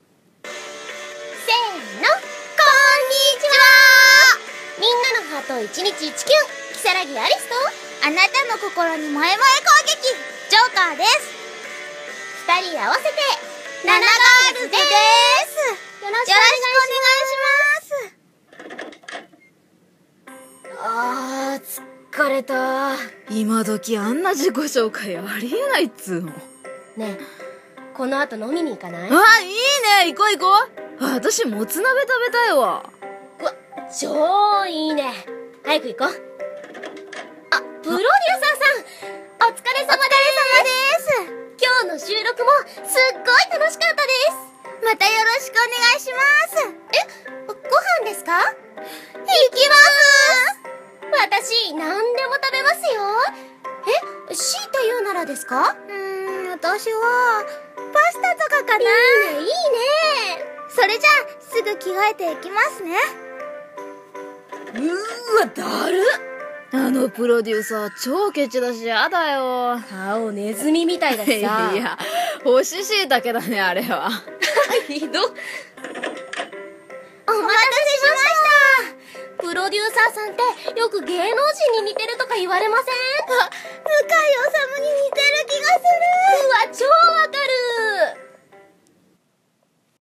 【声ギャップ声劇】